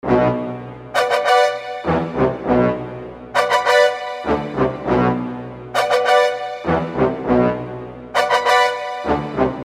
这是一个非常快速的混合。
他弹钢琴和唱歌 我弹贝斯和唱歌
标签： 钢琴 小号 铜管 低音 男声 女声 和声
声道立体声